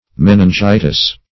Meningitis \Men`in*gi"tis\, n. [NL.